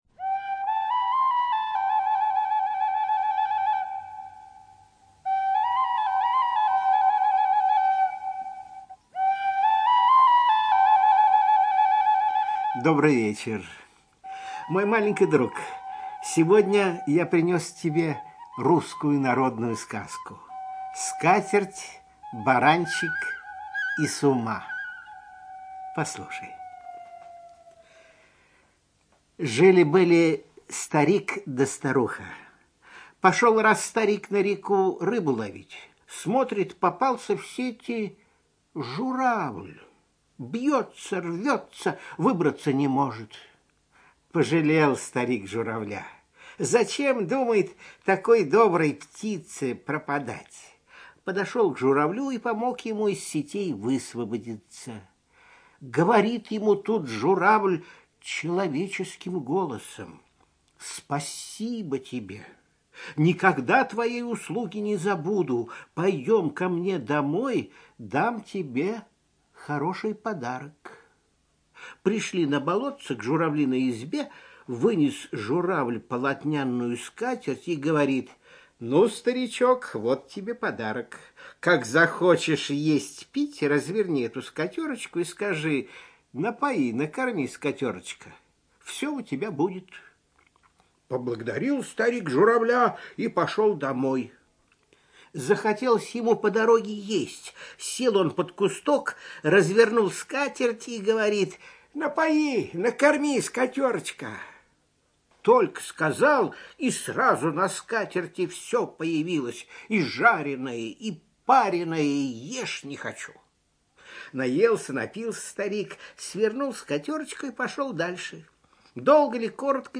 ЧитаетЛитвинов Н.